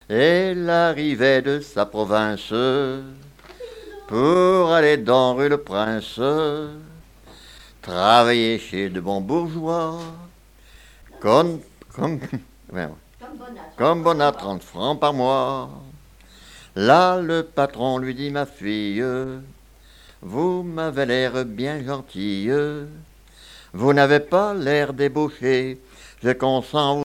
Localisation Palluau
Genre strophique
danses à l'accordéon diatonique et chansons
Pièce musicale inédite